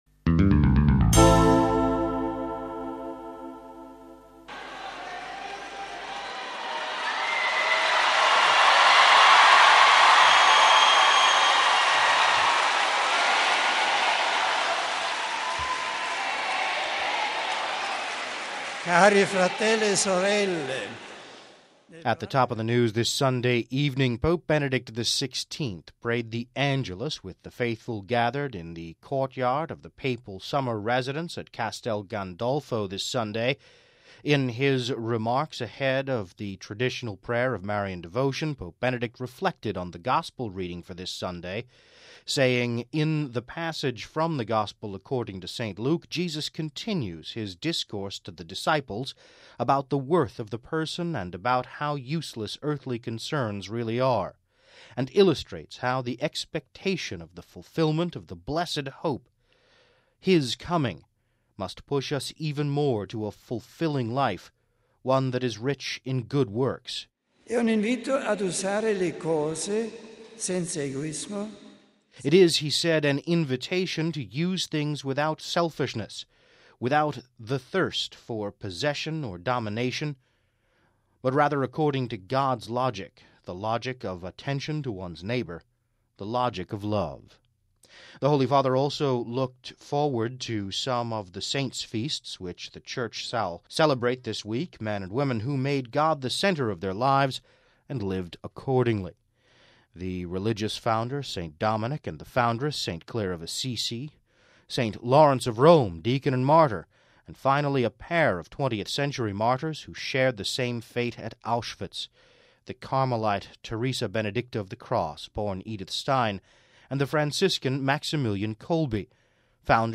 (08 Aug 10 – RV) Pope Benedict XVI prayed the Angelus with the faithful gathered in the courtyard of the Papal Summer residence at Castel Gandolfo this Sunday.
After the Angelus prayer, Pope Benedict greeted pilgrims in many languages, including English…